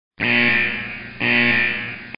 jingbao.mp3